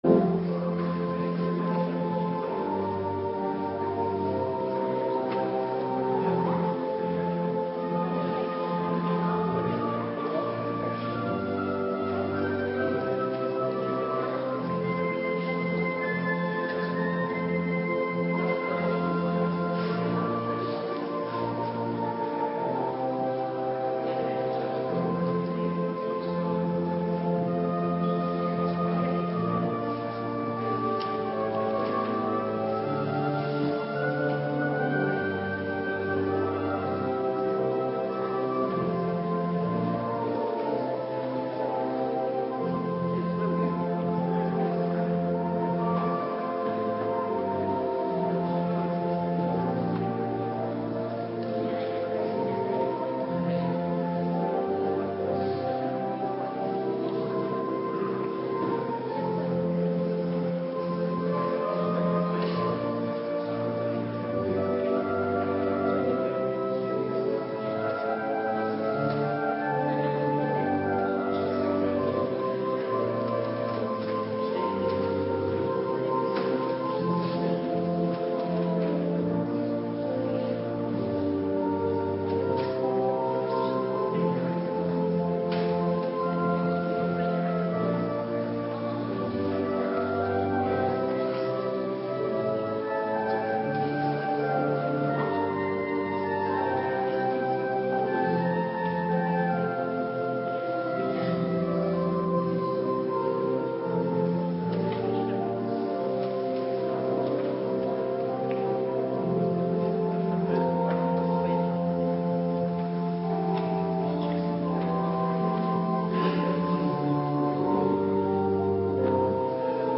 Avonddienst Nabetrachting Heilig Avondmaal - Cluster 3
Locatie: Hervormde Gemeente Waarder